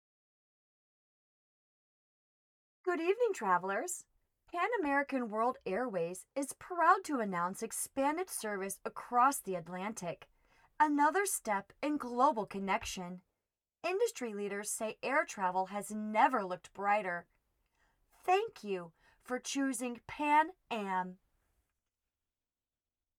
Professional-grade recording equipment and acoustically treated space
Commercial